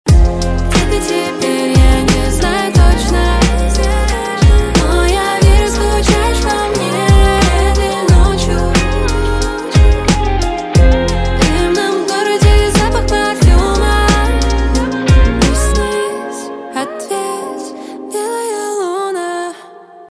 СКАЧАТЬ РИНГТОН (51)